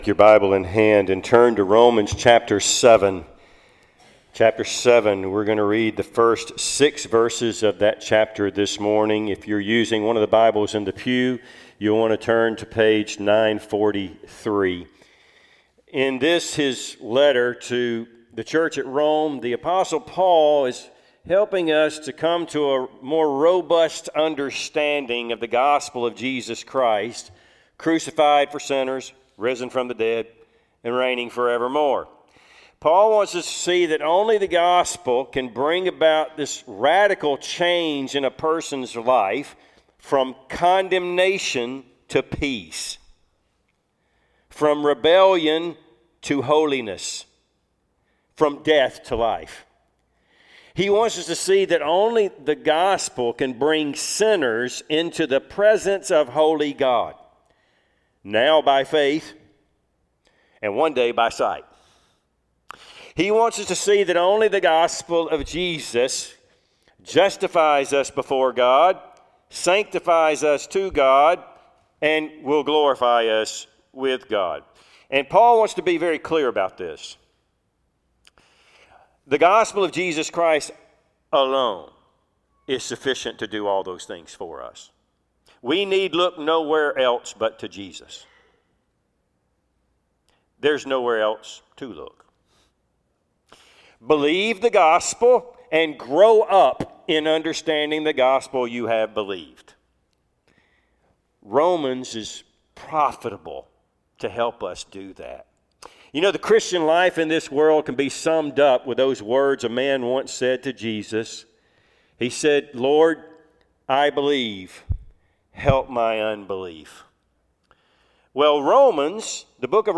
Passage: Romans 7:1-6 Service Type: Sunday AM